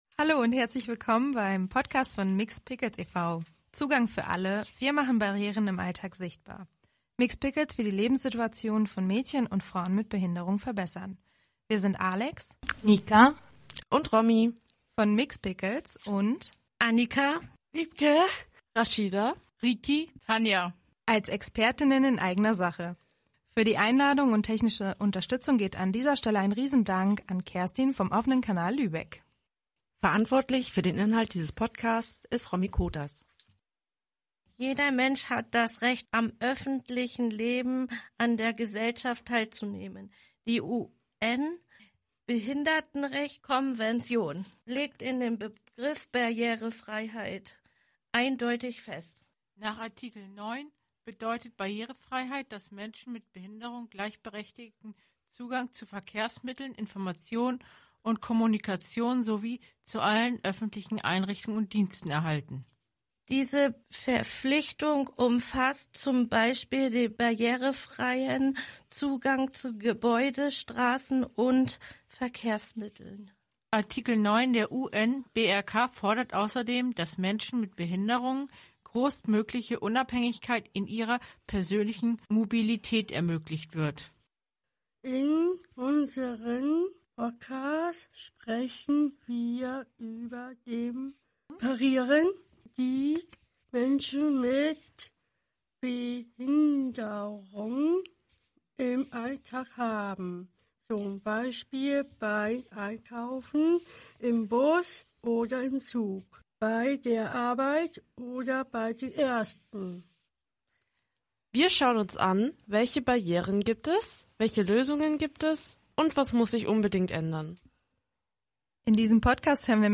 Mixed Pickles ohne Musik.MP3